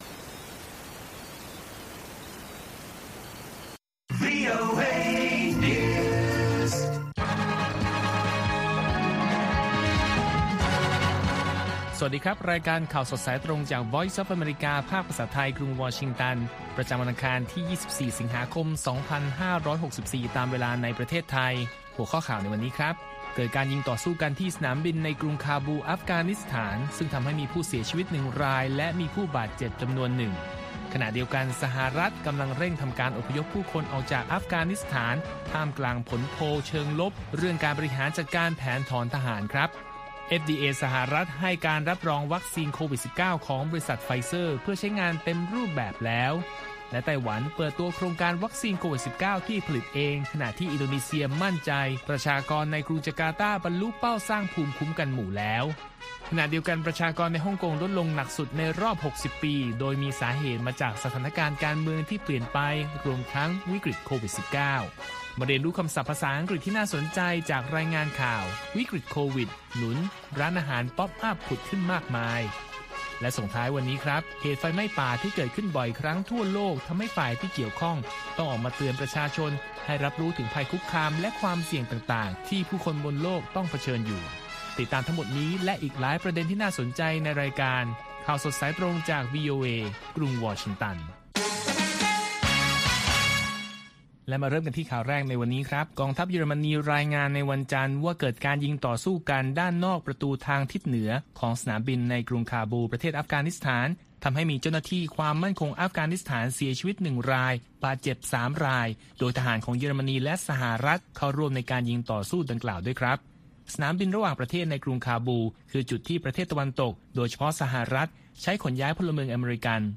ข่าวสดสายตรงจากวีโอเอ ภาคภาษาไทย ประจำวันอังคารที่ 24 สิงหาคม 2564 ตามเวลาประเทศไทย